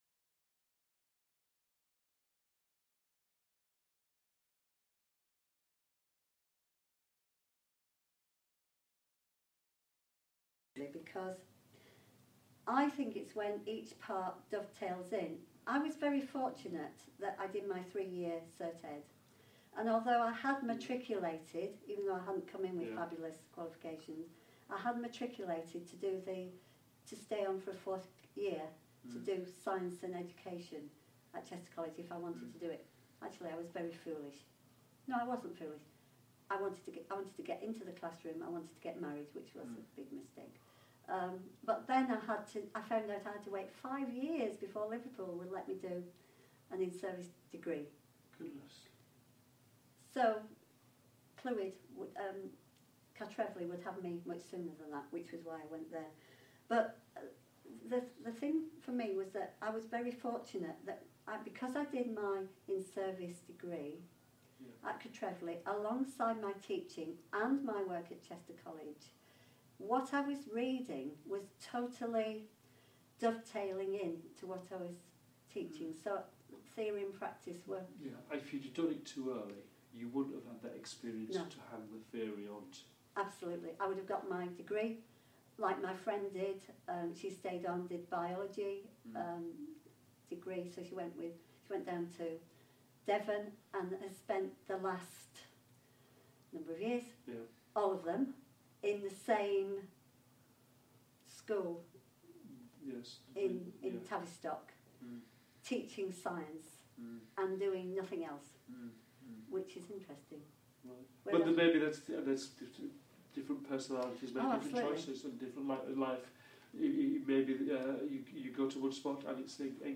Part of a series of interviews with veteran teachers and teacher educators.